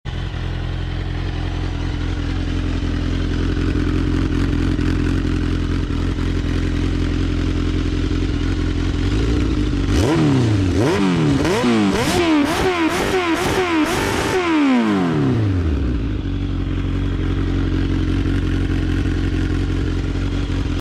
Soundcheck// "Roads Italia" Exhaust on sound effects free download
Soundcheck// "Roads Italia" -Exhaust on '01 GSX-R 1000